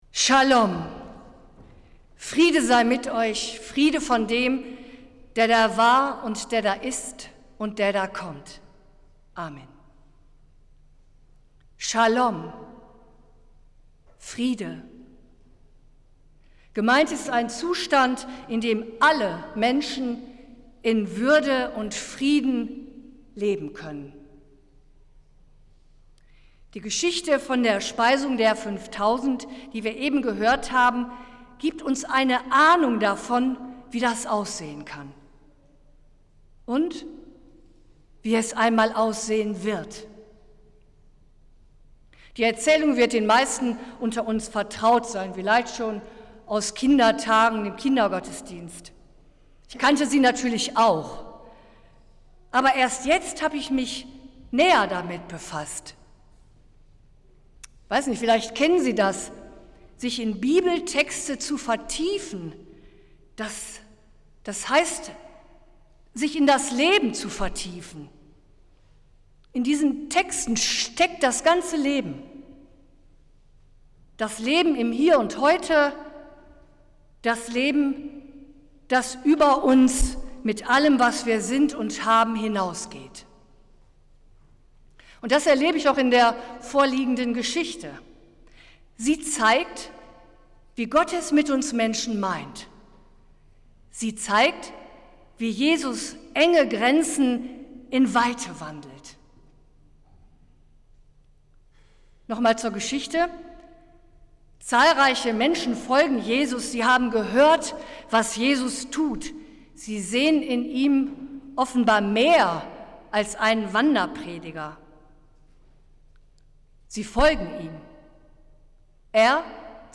Predigt des Gottesdienstes aus der Zionskirche am Sonntag, den 31.07.2022
Wir haben uns daher in Absprache mit der Zionskirche entschlossen, die Predigten zum Nachhören anzubieten.